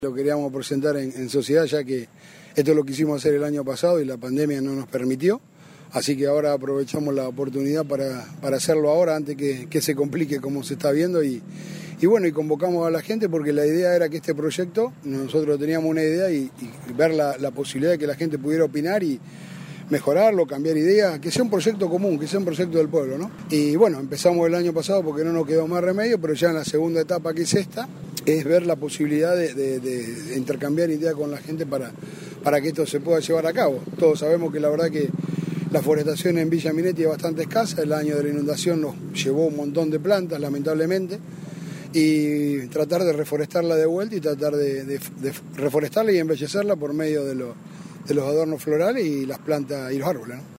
La presentación estuvo encabezada por el presidente comunal Gabriel Gentili, quien en diálogo con Radio EME brindó detalles del programa: